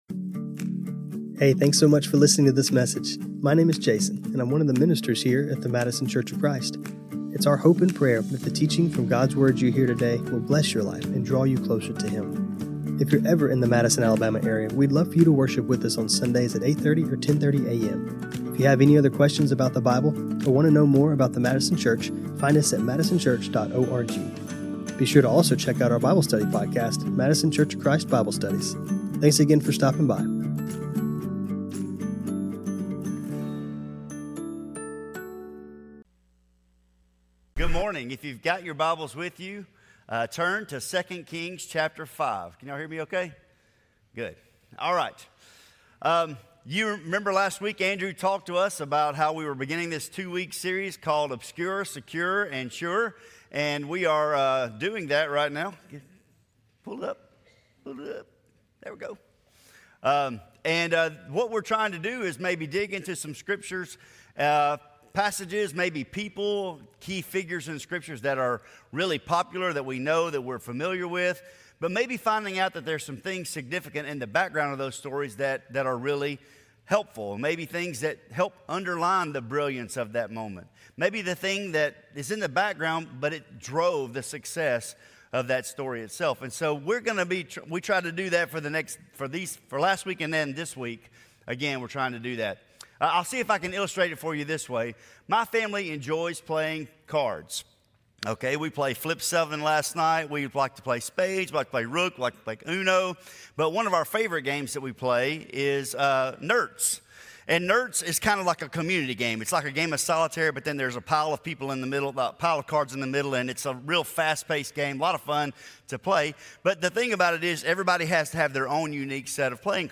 who helped spearhead the change in Naaman. 2 Kings 5:1-16 This sermon was recorded on Jan 25, 2026.